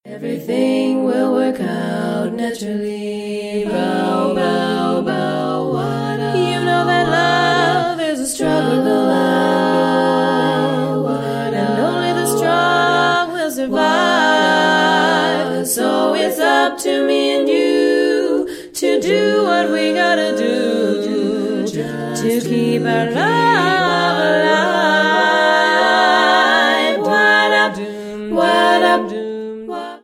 doo-wop barbershop!